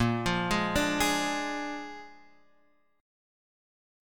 A#7 chord